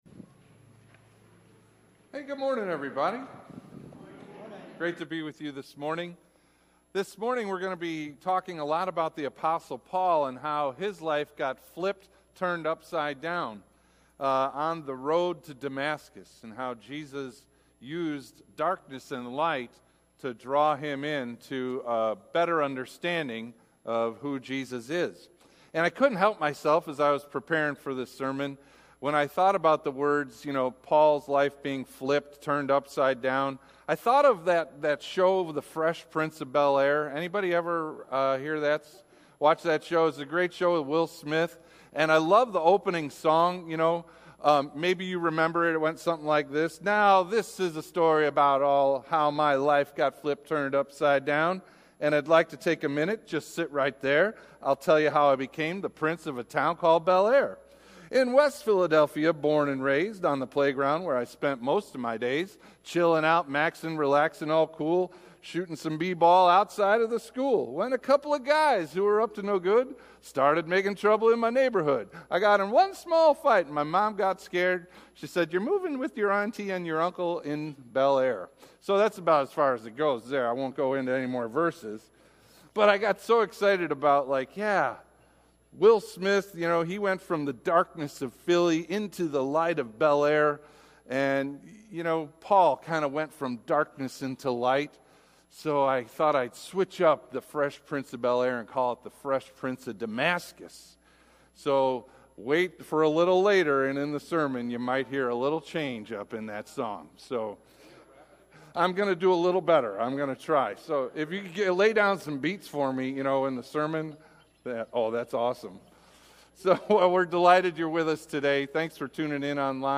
Mar 11 / Worship & Praise – The Fresh Prince of Damascus – Lutheran Worship audio